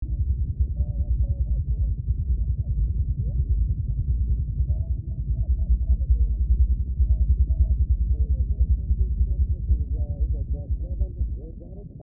Here's the exact same moment in the audio in these three synthesized channels:
-The LFE channel-
lfe-example.mp3